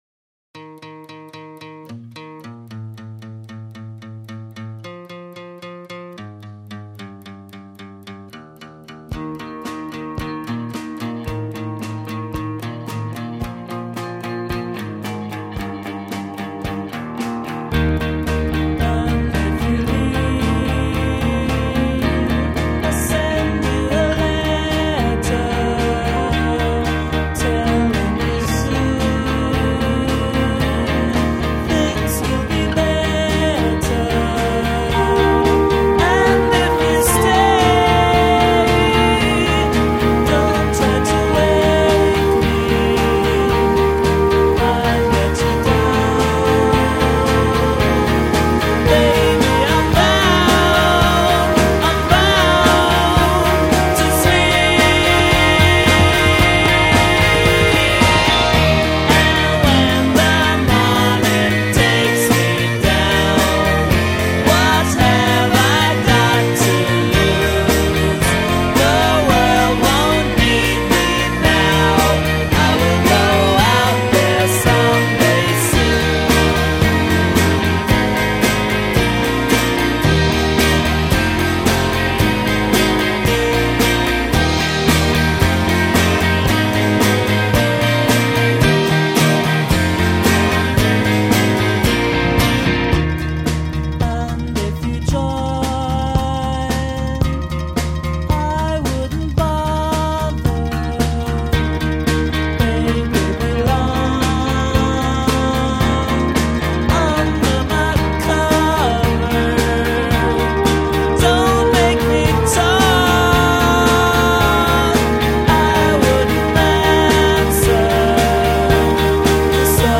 I have a weakness for Swedish pop